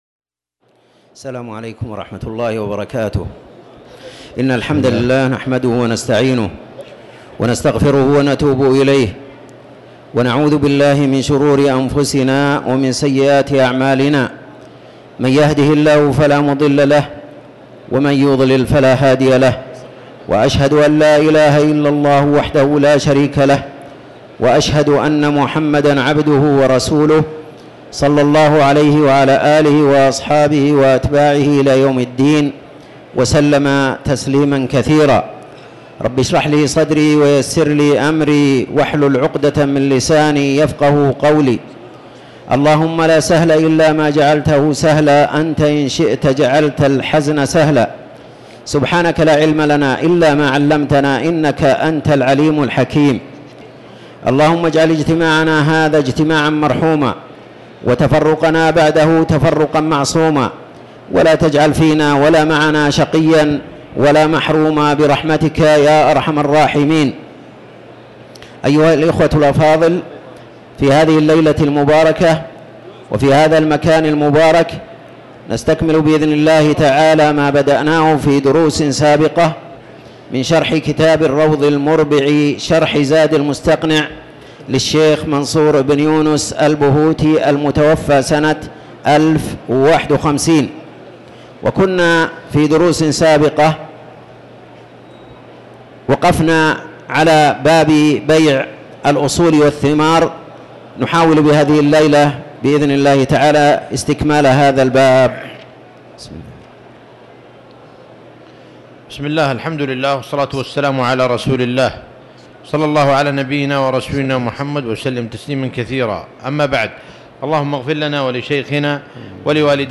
تاريخ النشر ١٢ رجب ١٤٤٠ هـ المكان: المسجد الحرام الشيخ